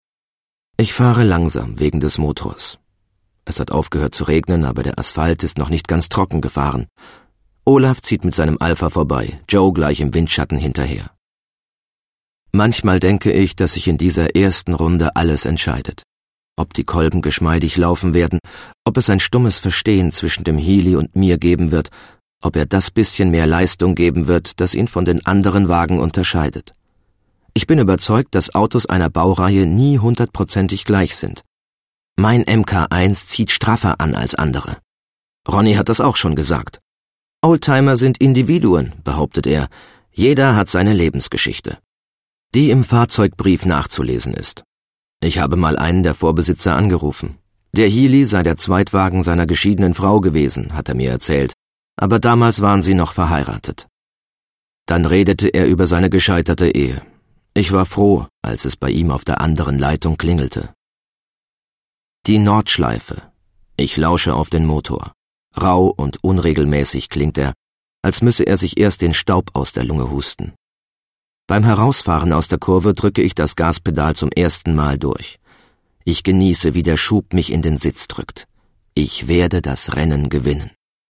sehr variabel
Mittel plus (35-65)
Commercial (Werbung)